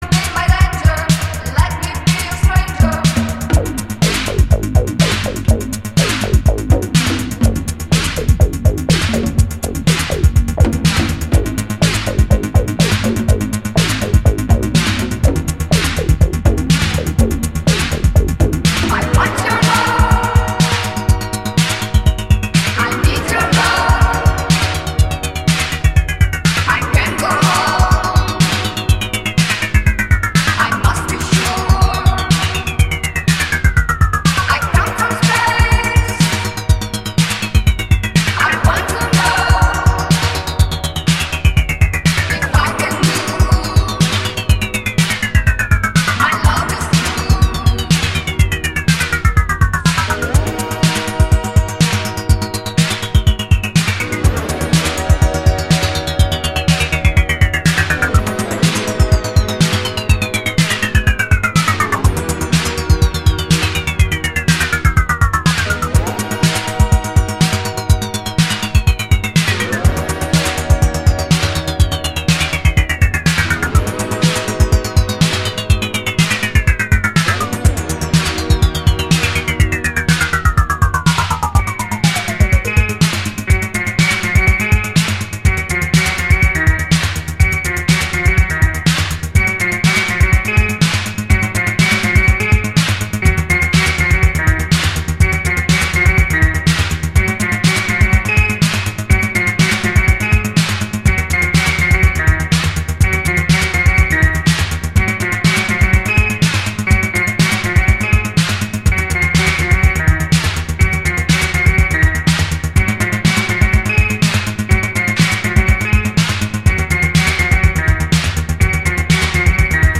One of the biggest italo classics around; must have !
who sings through a vocoder.